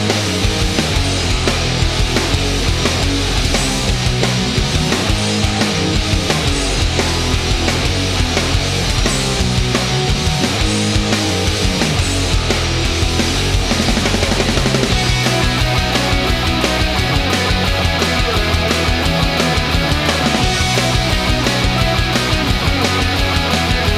no Backing Vocals Rock 3:52 Buy £1.50